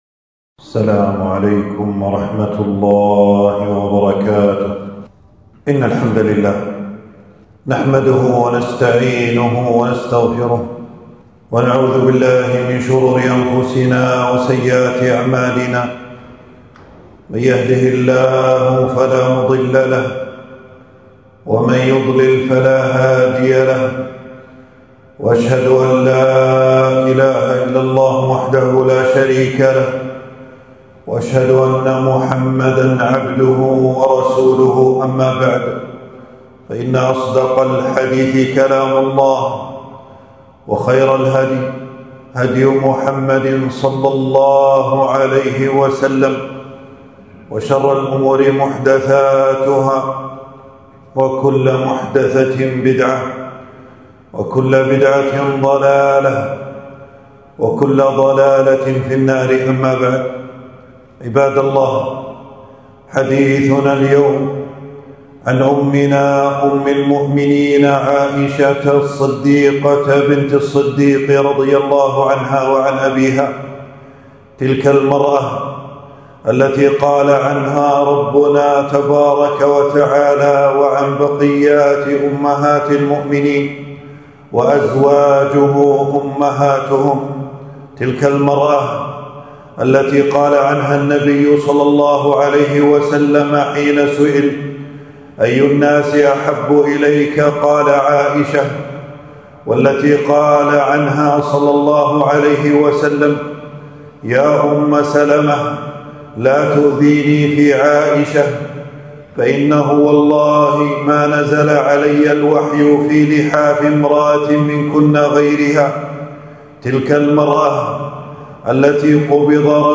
تنزيل تنزيل التفريغ خطبة بعنوان: حادثة الإفك وفضل عائشة رضي الله عنها.
المكان: في مسجد - أبو سلمة بن عبدالرحمن 22 شوال 1447هـ (بمدينة المطلاع).